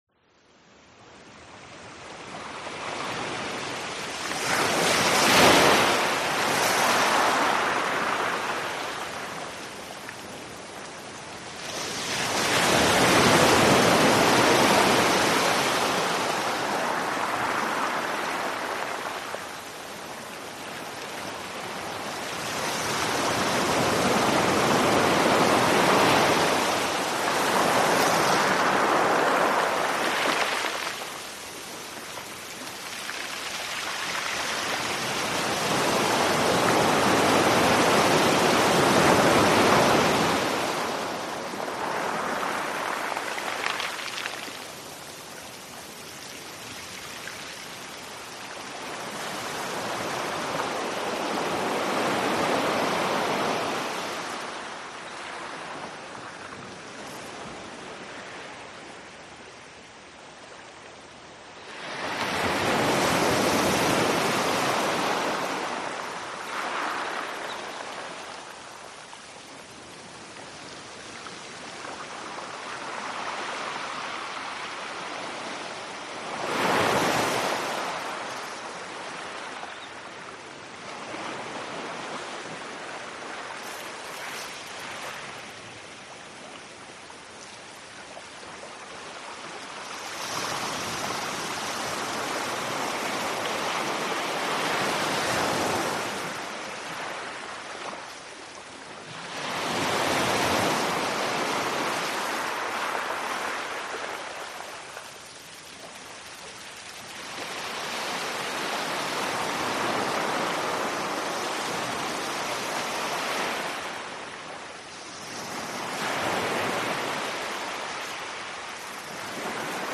Звуки моря, океана
Шум моря для сна